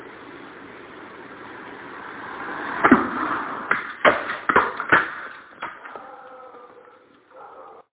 A surveillance camera captured the sound of meteorite fragments raining down on an outdoor patio table near Nançay, France, last week.
It's even rarer to *hear* it.
Then realized she had an audio recording of them landing!